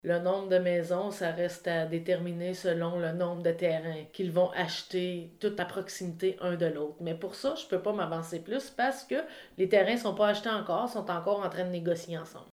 Des investisseurs envisagent de construire des maisons autonomes, alimentées par l’énergie solaire, à Déléage. La mairesse, Anne Potvin, a évoqué le nouveau développement que sa municipalité pourrait accueillir, lors de la période de questions au conseil municipal du 1er novembre.